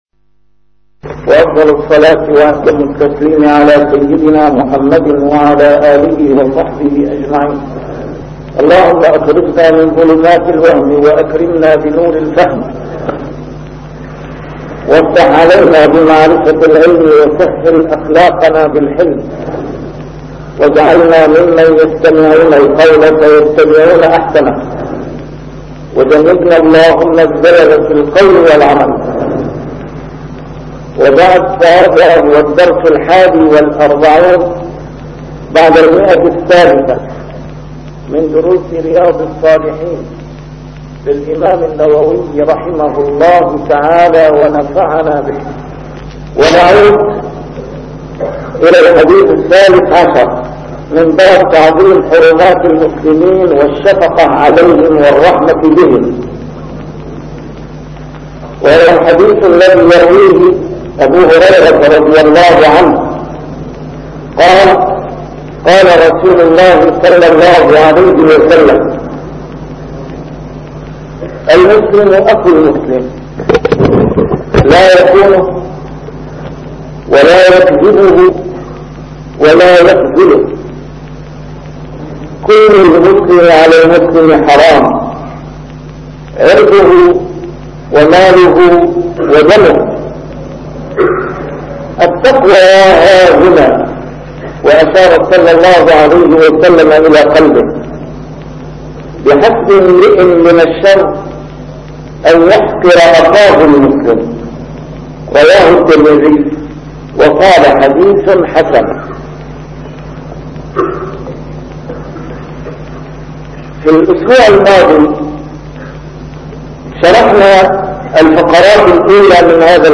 A MARTYR SCHOLAR: IMAM MUHAMMAD SAEED RAMADAN AL-BOUTI - الدروس العلمية - شرح كتاب رياض الصالحين - 341- شرح رياض الصالحين: تعظيم حرمات المسلمين